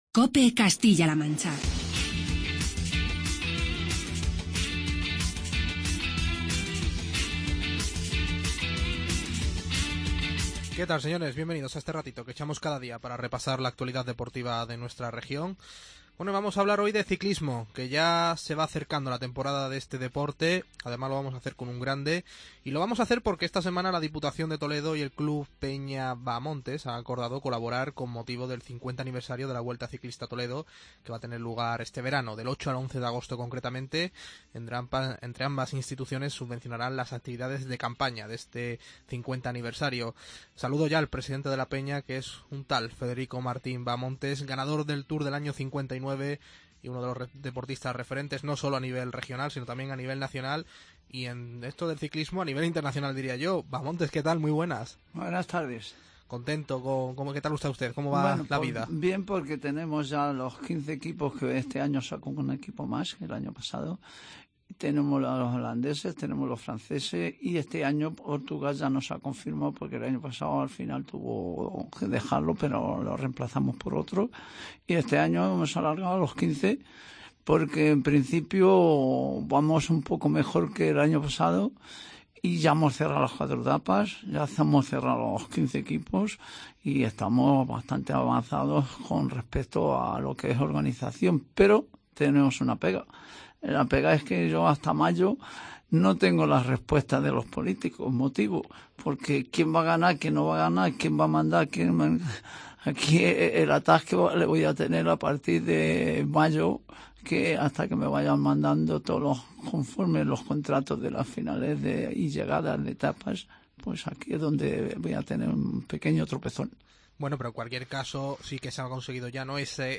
Charlamos con el ciclista Federico Martín Bahamontes